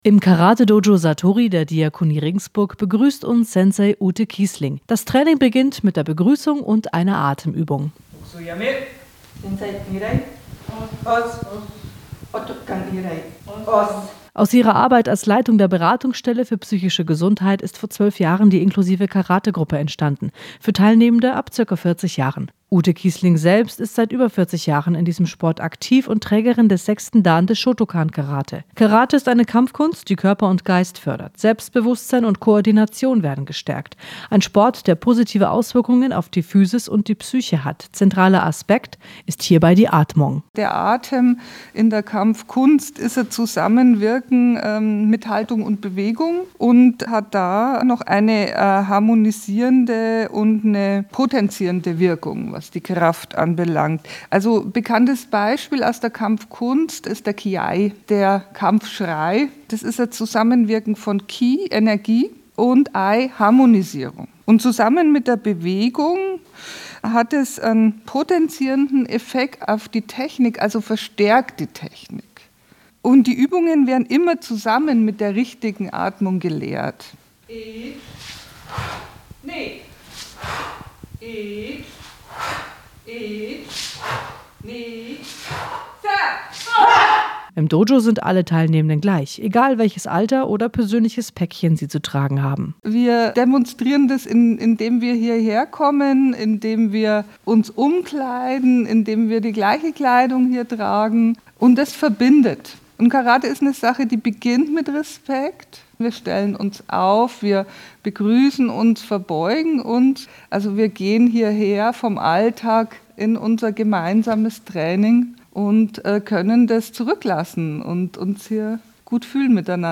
Radiobeitrag: Karategruppe